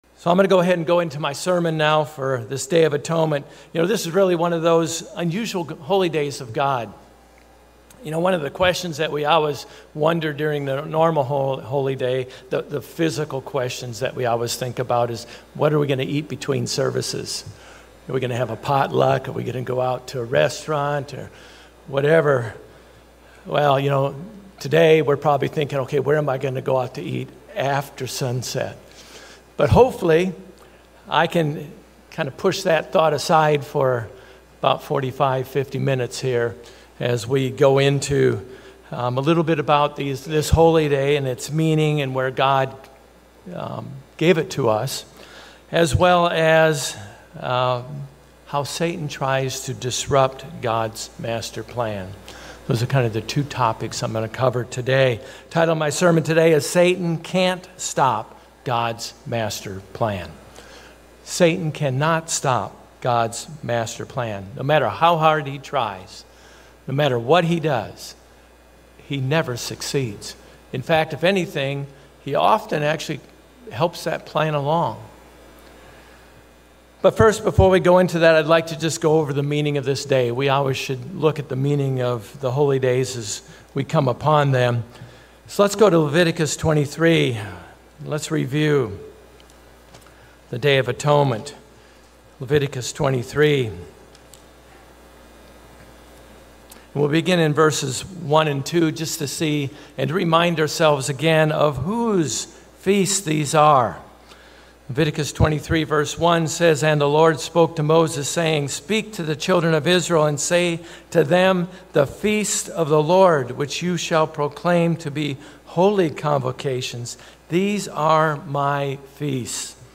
Given in Orlando, FL